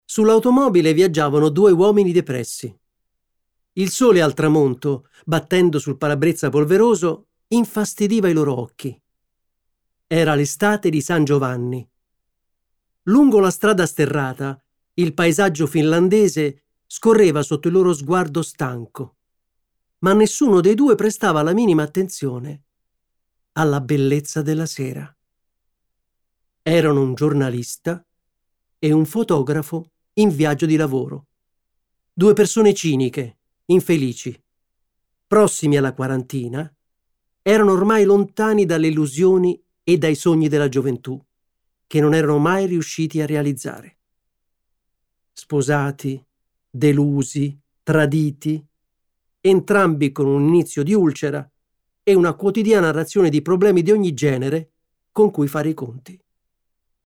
Audiolibro Emons Audiolibri 2015